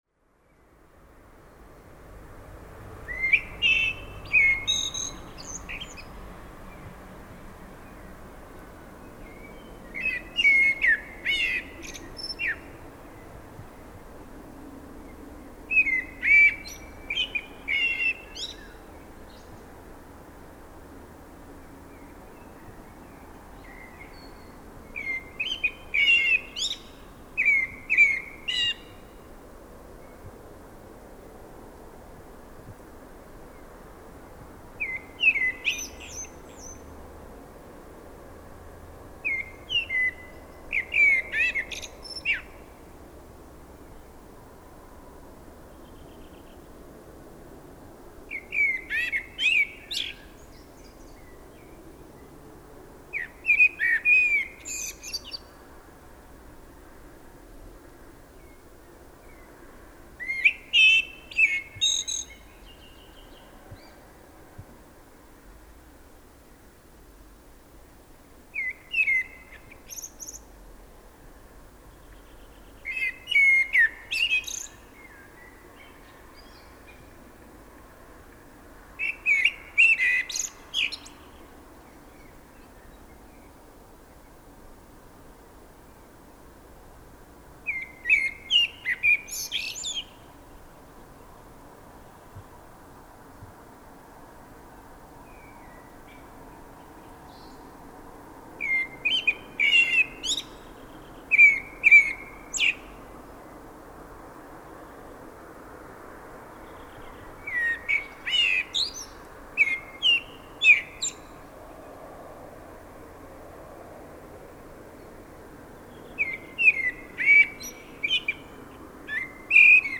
Svartþröstur í Smáíbúðahverfi
Hefur hann afar háværan en fagran söng sem oft getur verið unun á að hlýða.
Um daginn tókst mér samt að taka upp sönginn í fuglinum klukkan þrjú að morgni þar sem hann var í hvarfi við laufþykknið í næsta garði. En það stóð heima, þegar ég komst í sjónfæri við hann, þá þagnaði hann eftir þrjú síðustu versin í meðfylgjandi hljóðriti.
Tekið var upp á Korg MR1000 í 24bit/192Khz, Sound Device 302 formagnara, Telinga parabólu með Sennheiser MKE 2 lavalier hjóðnemum.